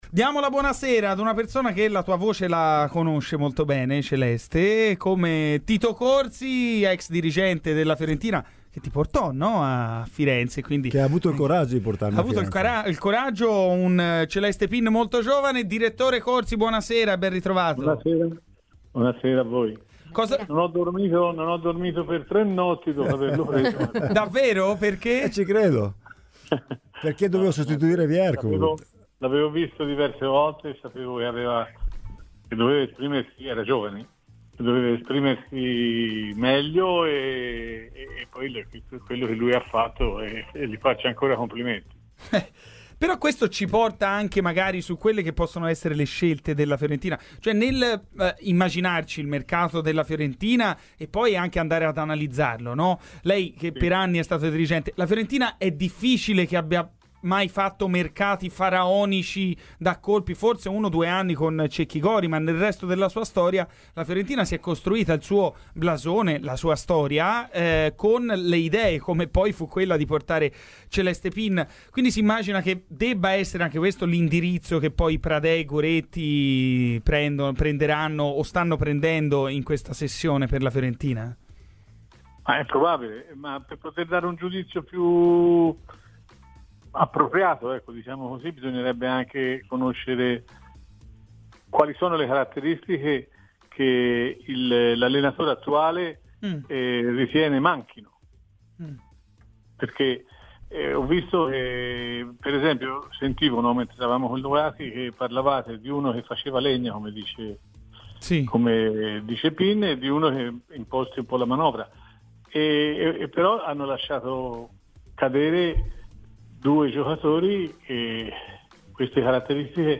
ha parlato ai microfoni di Radio FirenzeViola, nella trasmissione "Garrisca al Vento"